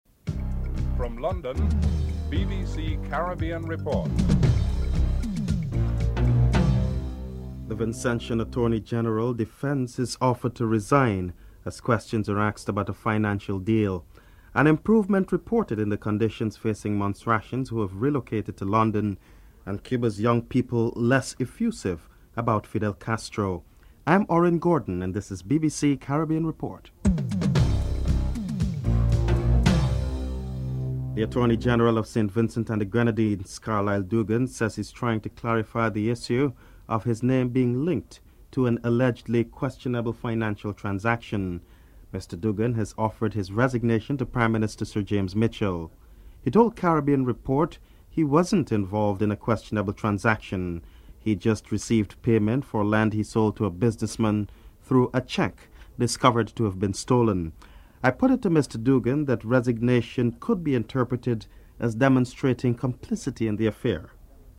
dc.description.tableofcontents2. The Vincentian Attorney General defends his offer to resign as questions are asked about a financial deal. Attorney General Carlyle Dougan is interviewed (00:32:02:18)en_US
Minister of Foreign Affairs Ralph Maraj is interviewed (05:14-07:24)en_US